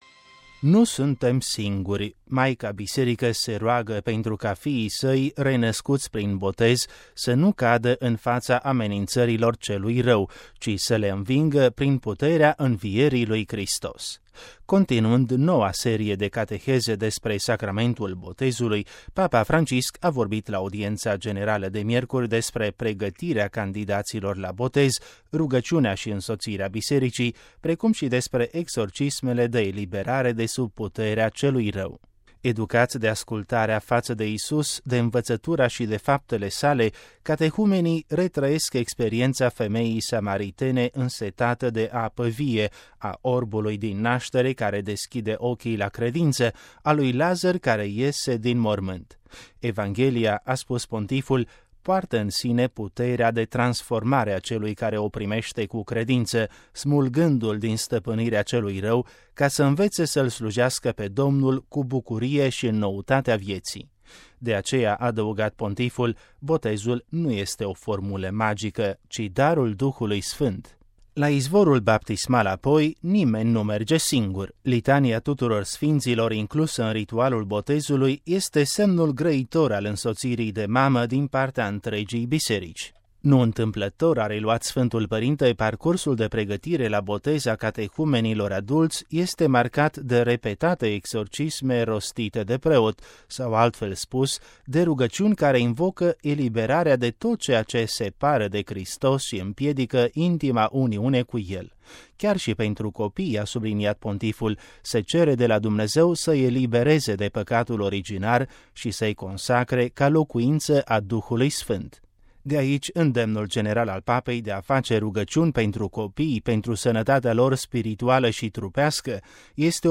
Audiența generală. Papa Francisc: Botezul nu este o magie, ci un dar al Duhului Sfânt